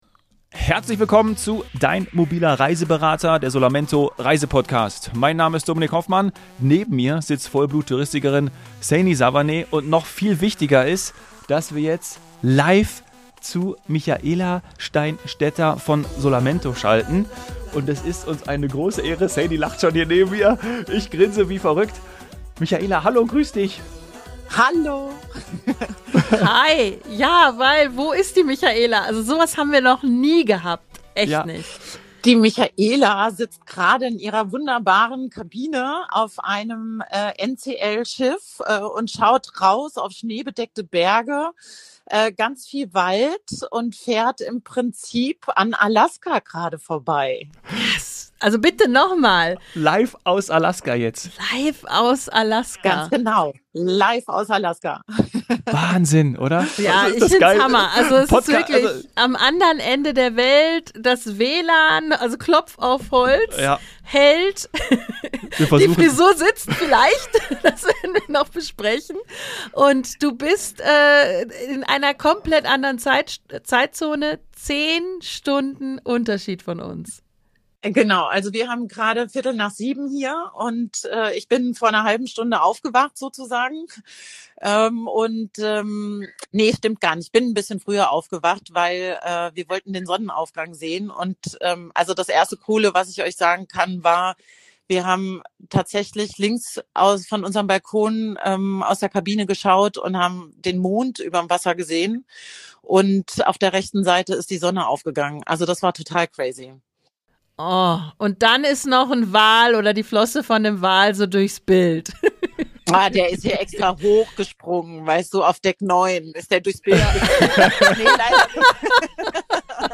#89 Live aus Alaska